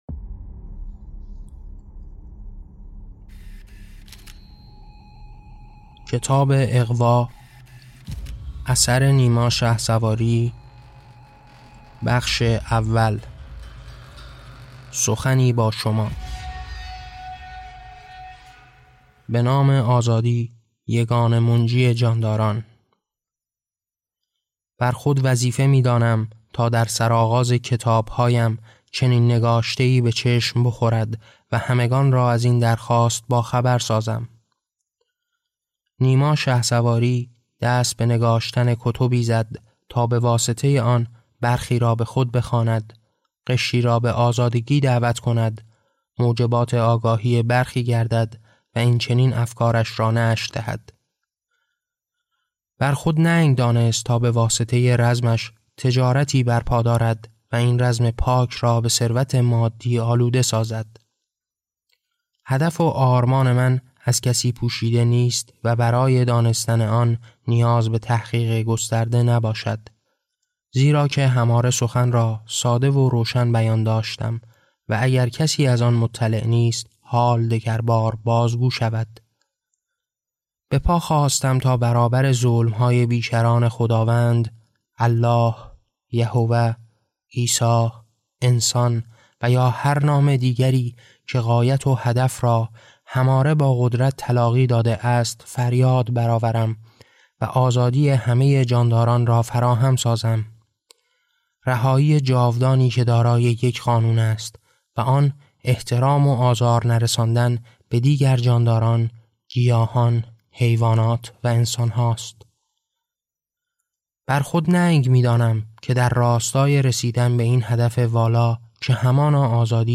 کتاب صوتی «اغوا»
این نسخه شنیداری با کیفیت استودیویی جهت غوطه‌وری کامل در مفاهیم اثر تهیه شده است.